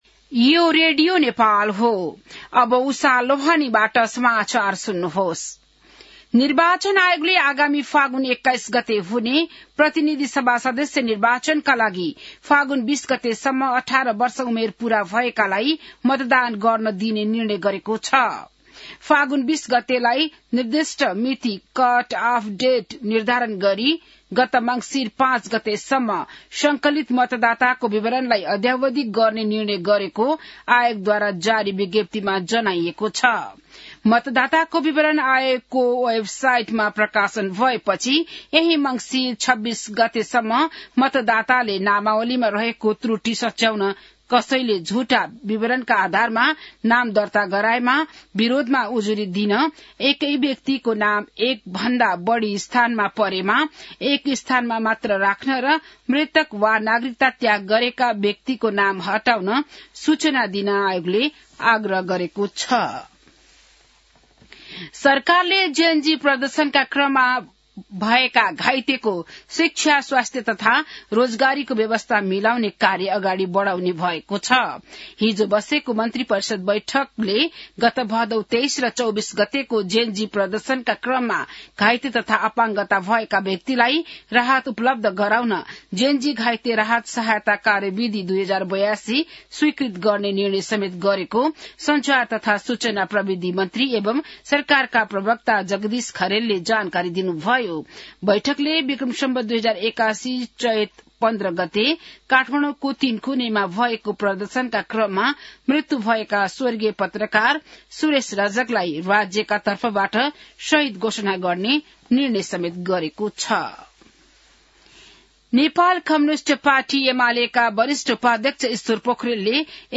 बिहान १० बजेको नेपाली समाचार : २३ मंसिर , २०८२